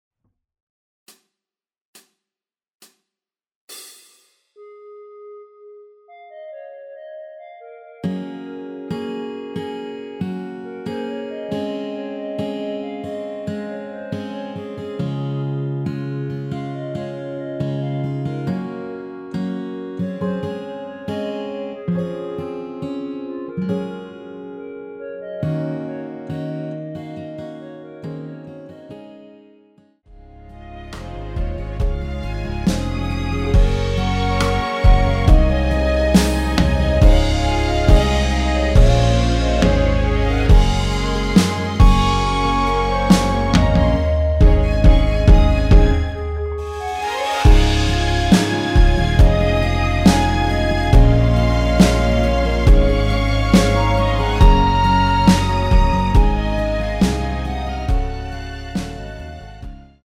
전주 없이 시작하는 곡이라서 시작 카운트 만들어놓았습니다.(미리듣기 확인)
원키에서(+5)올린 멜로디 포함된 MR입니다.
Db
앞부분30초, 뒷부분30초씩 편집해서 올려 드리고 있습니다.
중간에 음이 끈어지고 다시 나오는 이유는